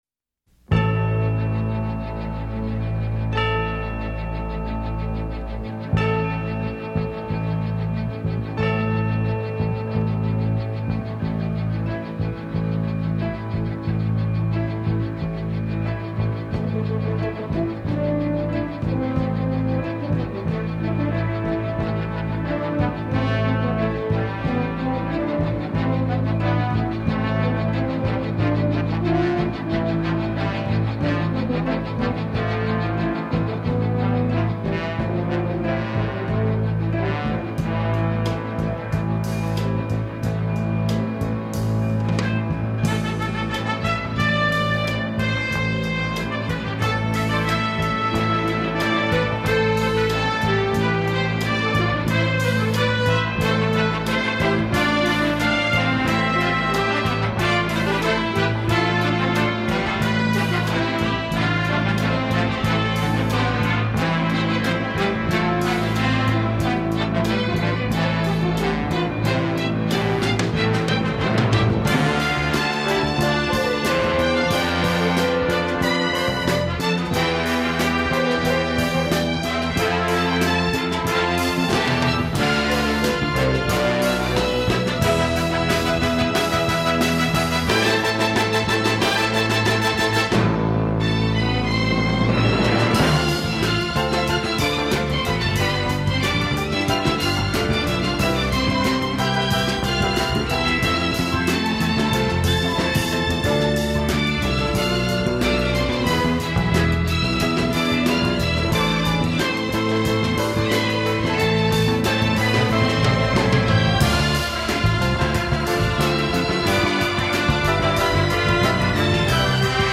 （BGM